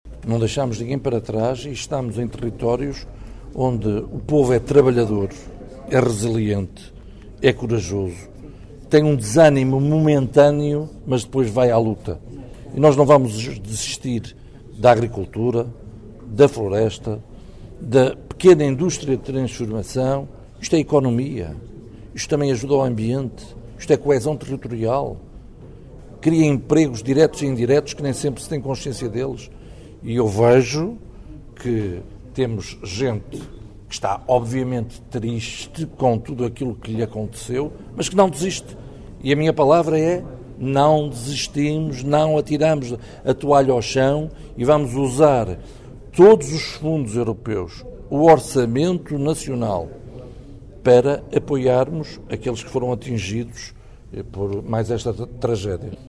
Declarações do ministro da Agricultura, José Manuel Fernandes, ontem à tarde, em Vila Pouca de Aguiar, onde na semana passada arderam mais de 10 mil hectares de floresta, mato e área agrícola.